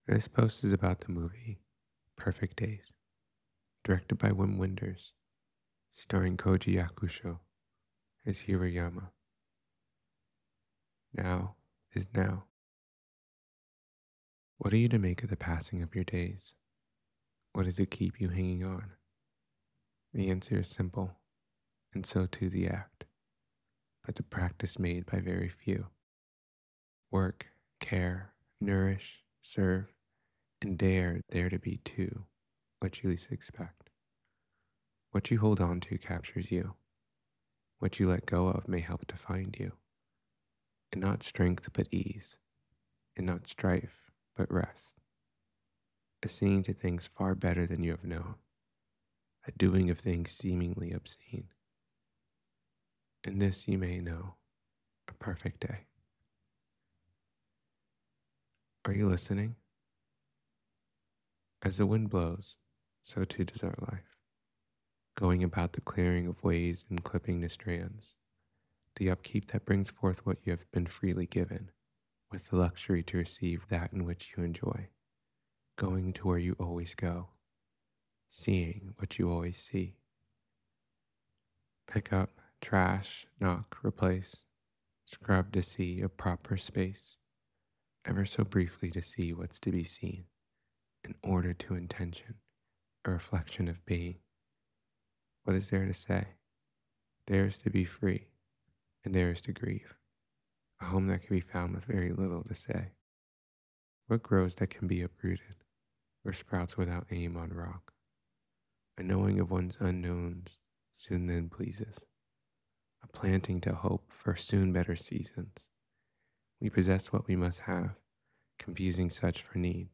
perfect-days-to-know-a-story-reading.mp3